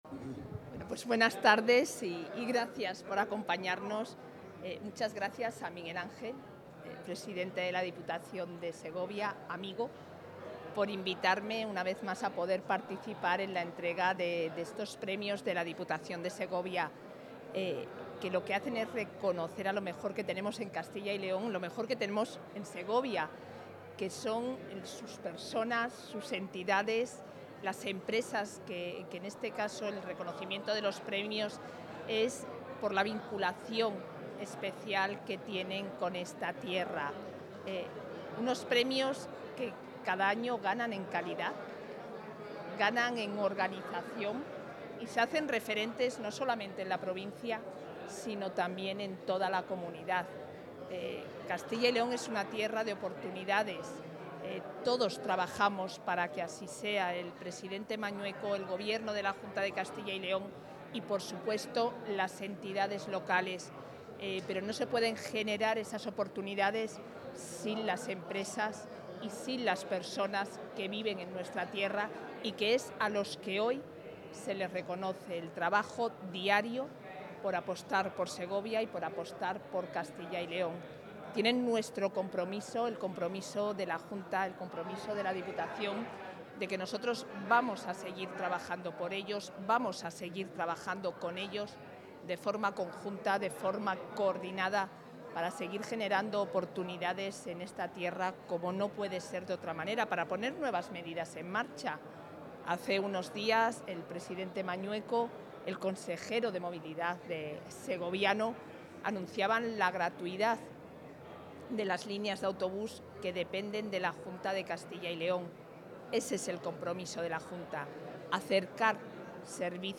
La vicepresidenta de la Junta asiste al acto de entrega de los Premios Diputación de Segovia 2024
Intervención de la vicepresidenta.